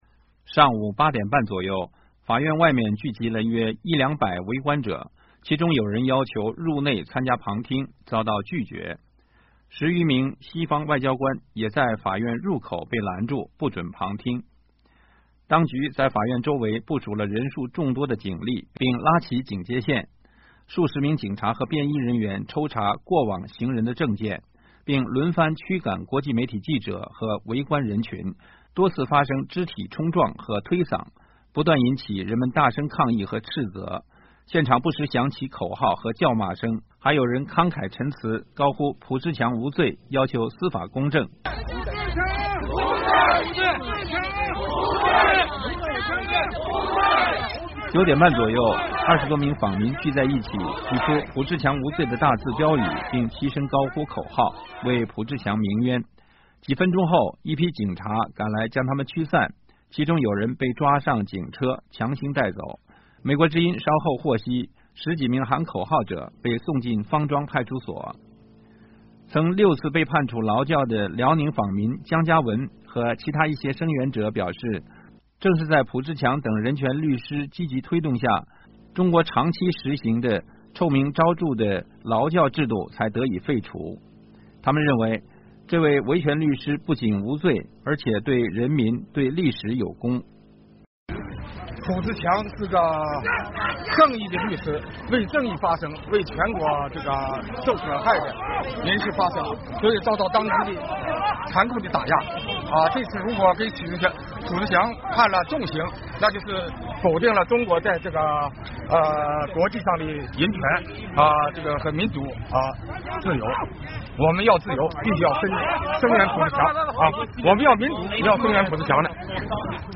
现场不时响起口号和叫骂声， 还有人慷慨陈词，高呼“浦志强无罪”，要求“司法公正”。